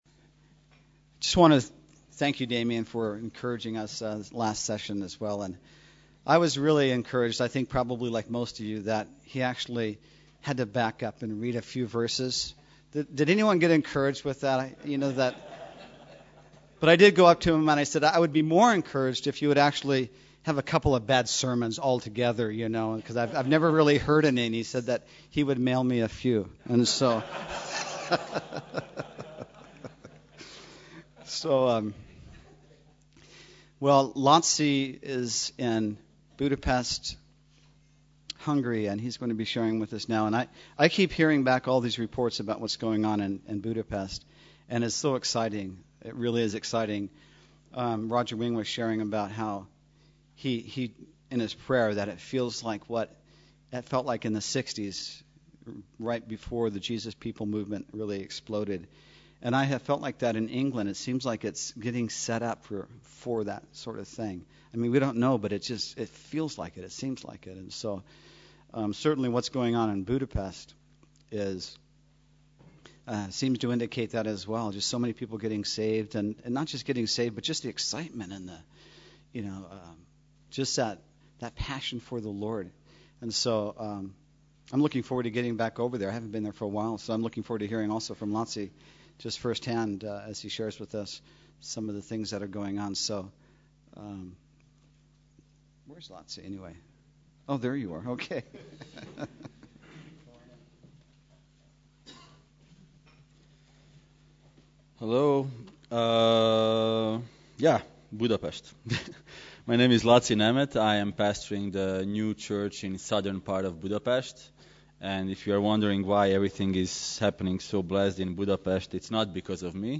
Messages en anglais donnés au Centre de Retraites de Calvary Chapel au Schlöss Heroldeck à Millstatt, en Autriche... ...